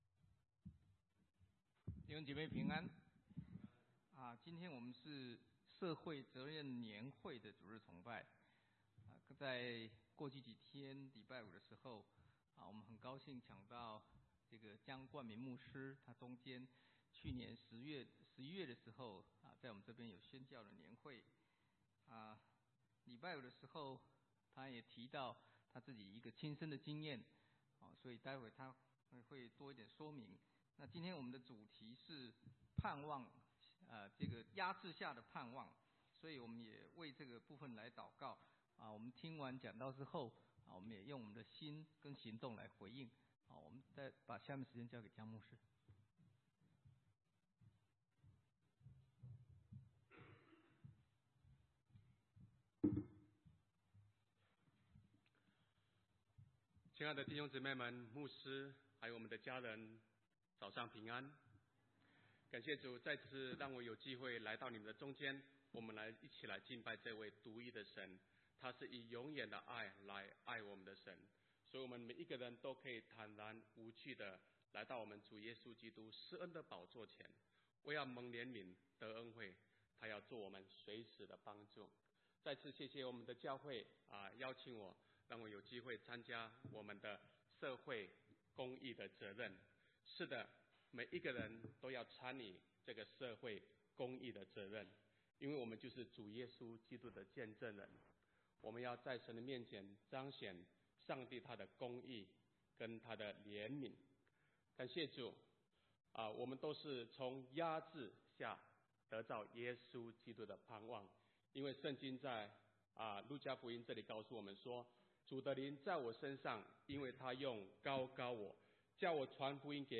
» 下載錄音 (很抱歉，这段录音有一些录制上的困难)